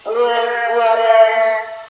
1) nejsme „BLUEZGUARE“, jak tuhle zaznělo z nejmenovaných obecních tlampačů: